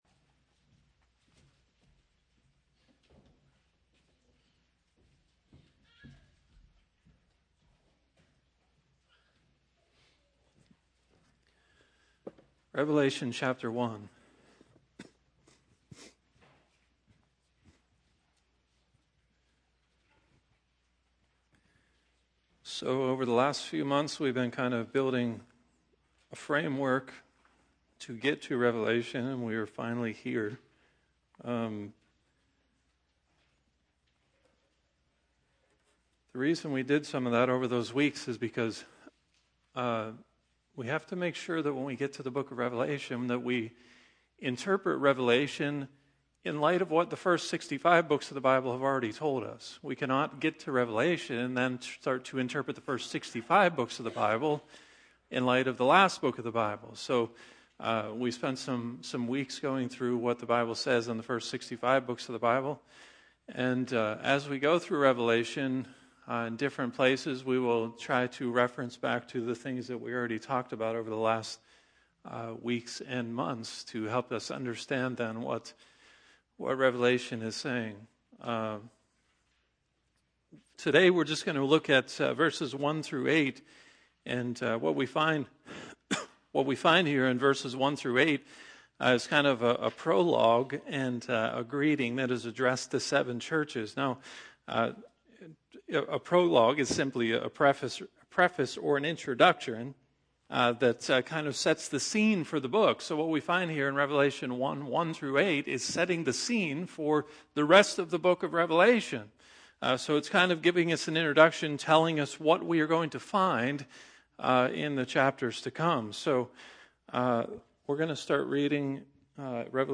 Sermons | Barrs Mill Church of God